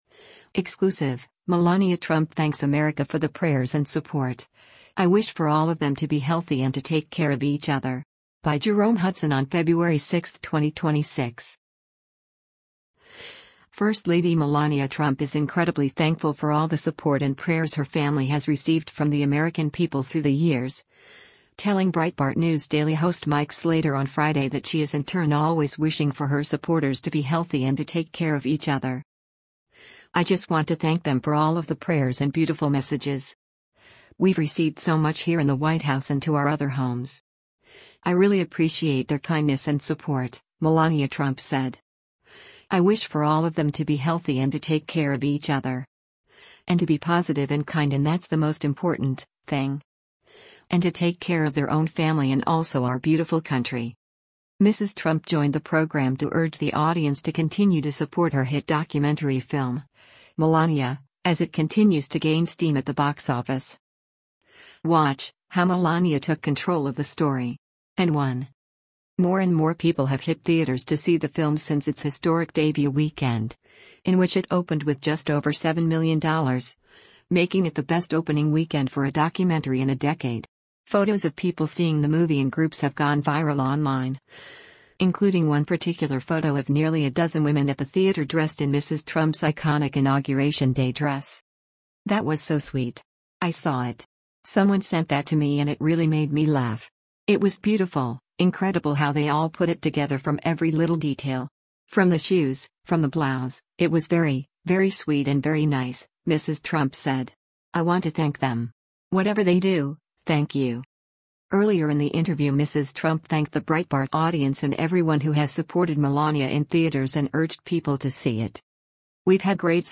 Earlier in the interview Mrs. Trump thanked the Breitbart audience and everyone who has supported Melania in theaters and urged people to see it.